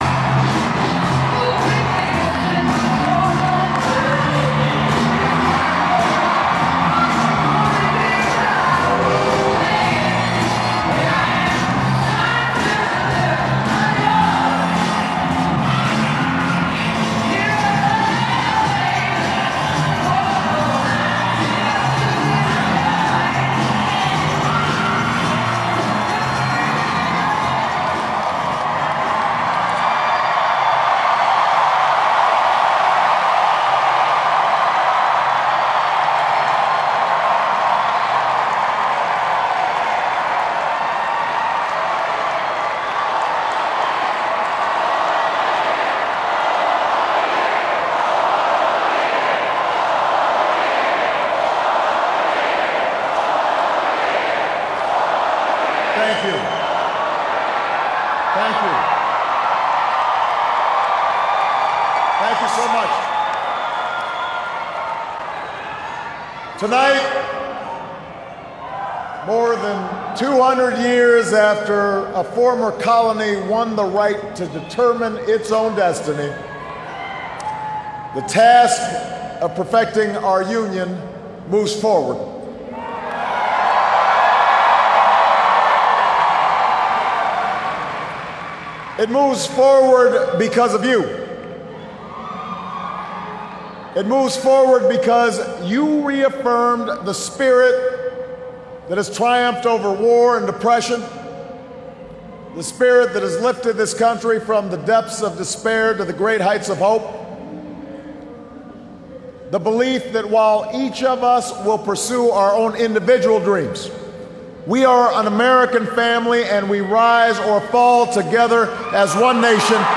መደረ ኦባማ(እንግሊዘኛ)